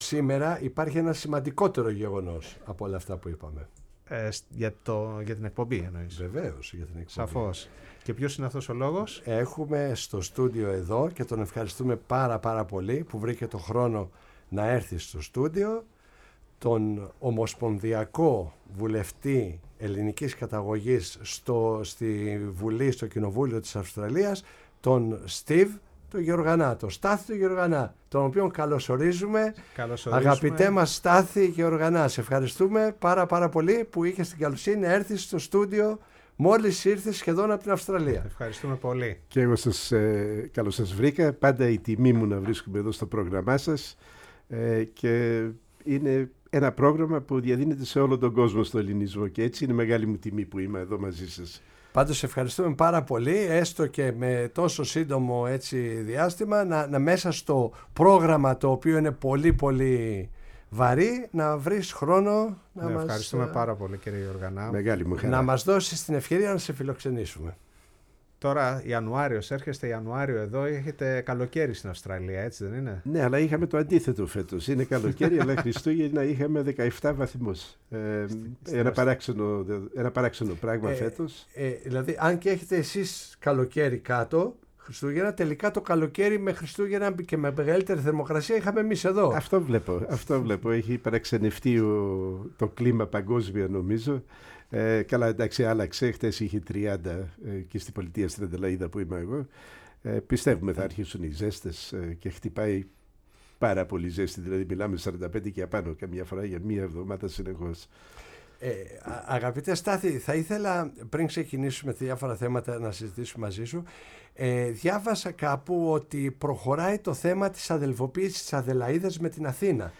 Η ΦΩΝΗ ΤΗΣ ΕΛΛΑΔΑΣ Η Παγκοσμια Φωνη μας ΣΥΝΕΝΤΕΥΞΕΙΣ Συνεντεύξεις Steve Georganas Αδελαιδα ΑΘΗΝΑ Αυστραλια ΕΕ ΕΛΛΑΔΑ εμπορικη συμφωνια με ΕΕ κοστος ζωης ΦΕΤΑ